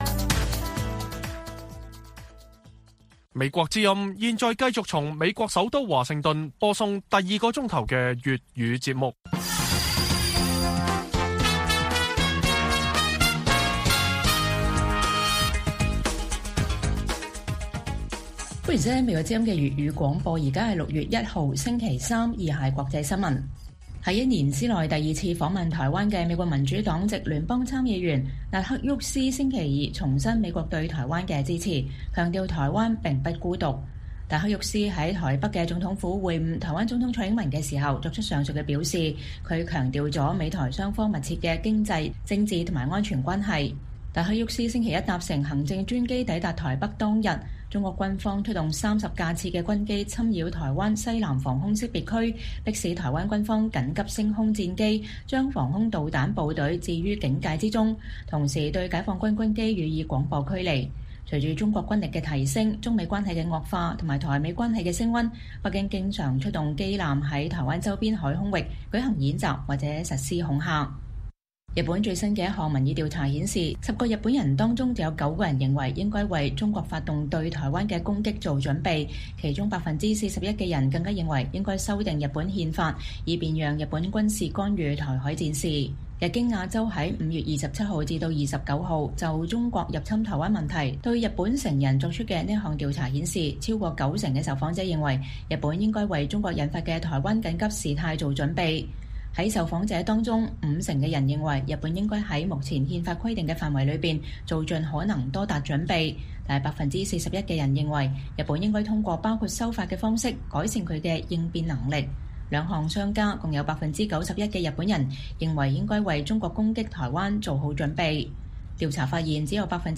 粵語新聞 晚上10-11點 : 六四33週年忌日 天安門母親呼籲中共勇於承認歷史責任